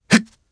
Theo-Vox_Landing_jp.wav